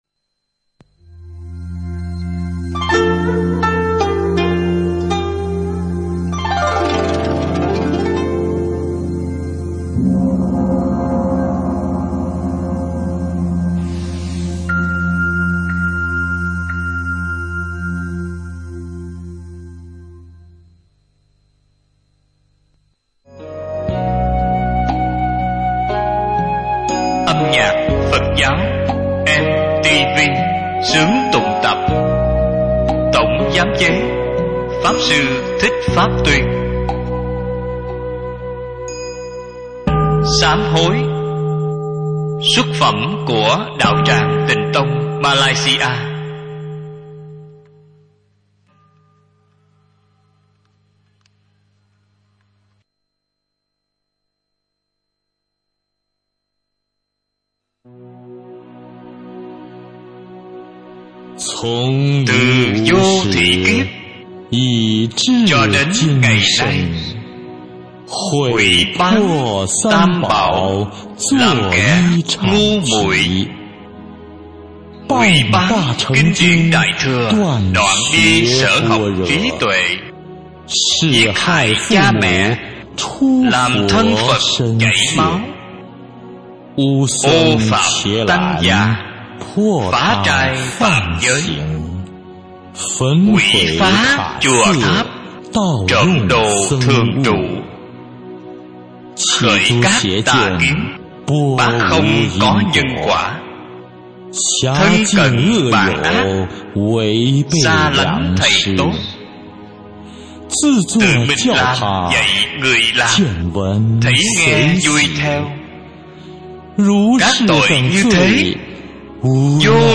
Tác giả: Đạo tràng Tịnh Tông Malaysia Thể loại: Nhạc Phật Giáo